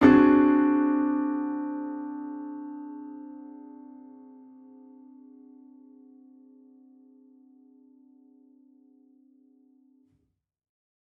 Index of /musicradar/gangster-sting-samples/Chord Hits/Piano
GS_PiChrd-D7b5.wav